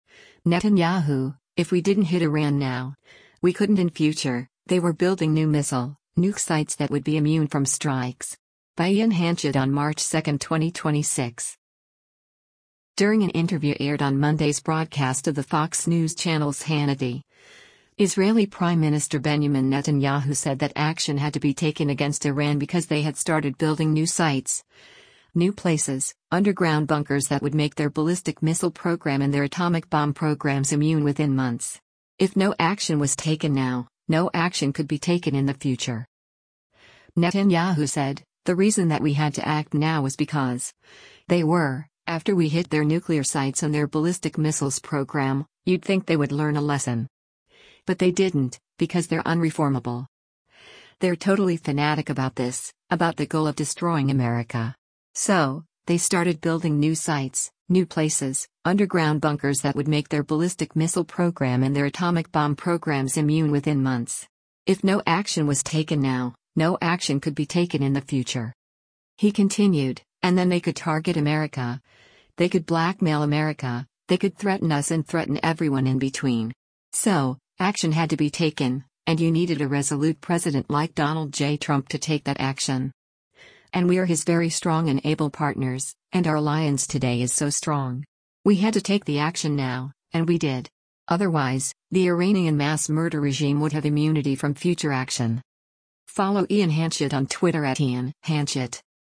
During an interview aired on Monday’s broadcast of the Fox News Channel’s “Hannity,” Israeli Prime Minister Benjamin Netanyahu said that action had to be taken against Iran because they had “started building new sites, new places, underground bunkers that would make their ballistic missile program and their atomic bomb programs immune within months. If no action was taken now, no action could be taken in the future.”